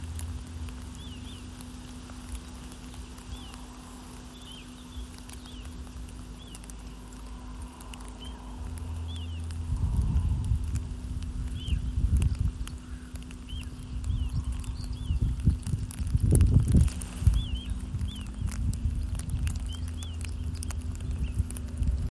Upes tārtiņš, Charadrius dubius
StatussTikko šķīlušies mazuļi vai vecāki ar mazuļiem (RM)